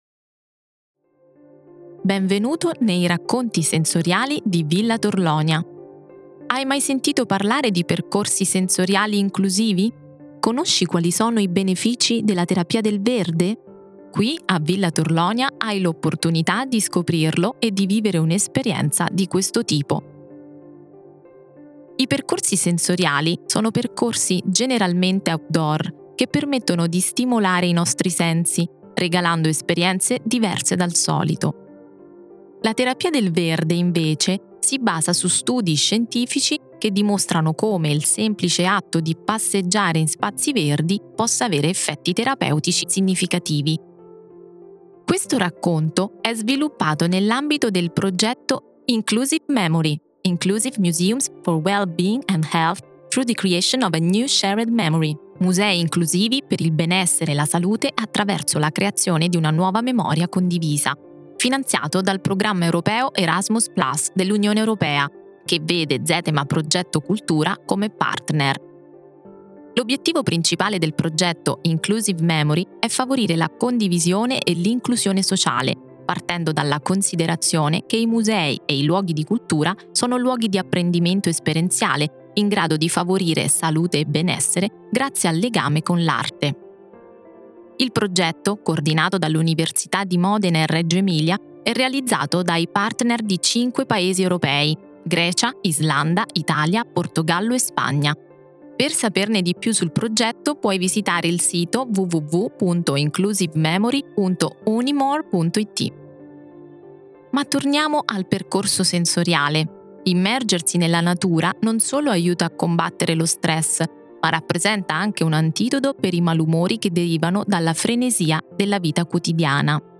INTRODUZIONE
Avrai la possibilità di esplorare in modo insolito il parco di villa Torlonia attraverso suoni evocativi e texture tattili.